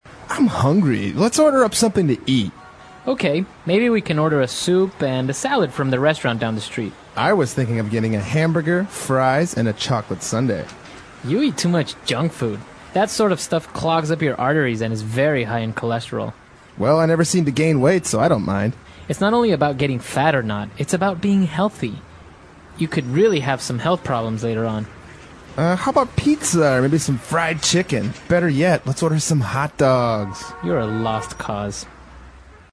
外教讲解纯正地道美语|第345期:Fast Food 快餐